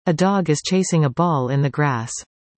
Finally, I converted the captions to speech by passing them through Google’s WaveNet (text-to-speech algorithm) available on the Google Cloud Platform, using Python’s text to speech library.